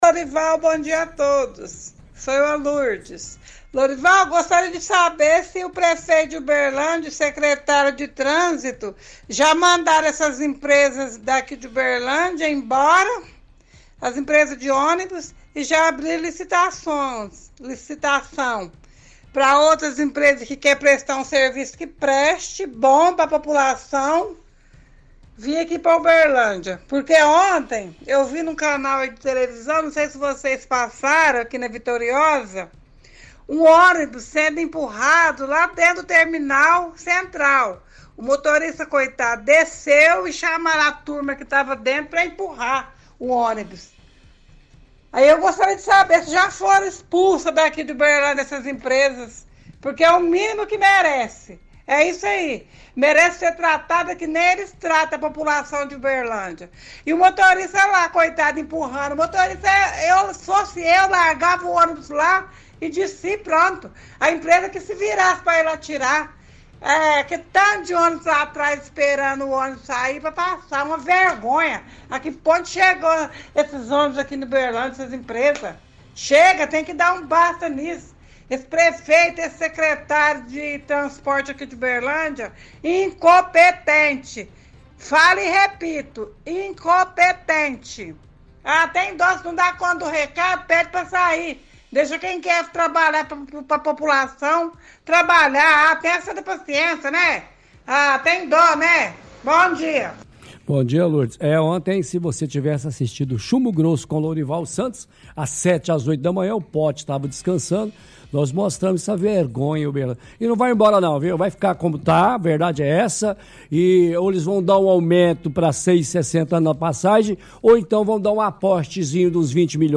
-Ouvinte pergunta se prefeito e secretário de trânsito já mandaram as empresas de ônibus embora e se já abriu novas licitações. Comenta sobre ônibus que passageiros empurraram no terminal no dia de ontem.